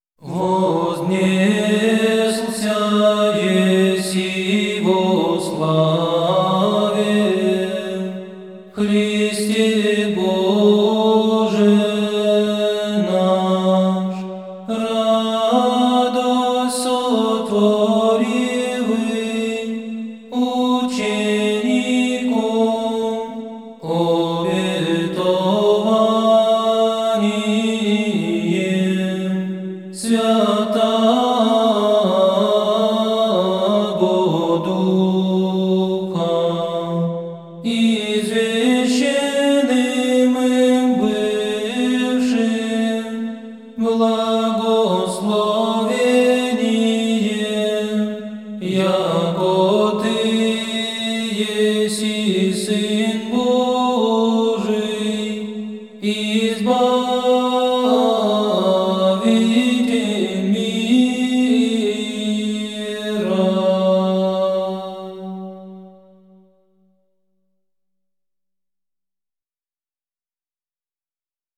Тропарь
Тропарь гл.4: